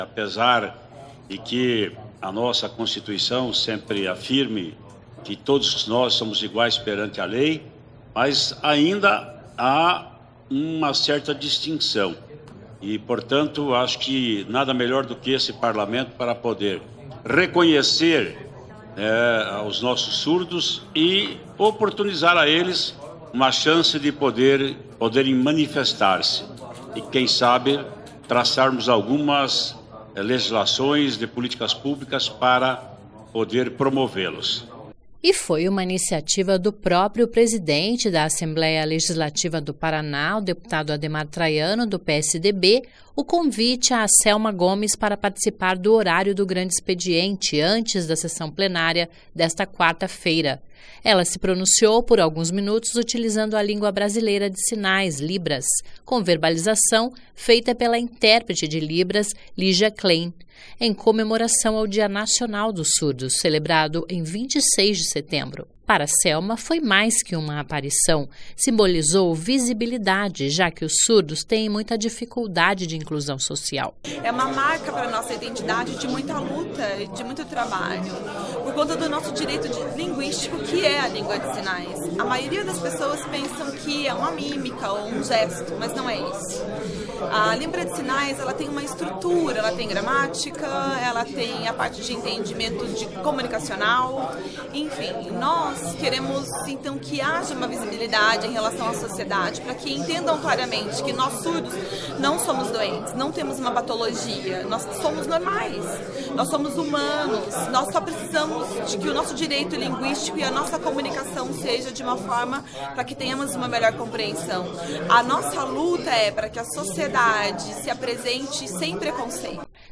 (Começa com sobe som do Traiano falando que pela primeira vez a Tribuna da Assembleia Legislativa é ocupada por deficiente auditivo e a importância desse reconhecimento))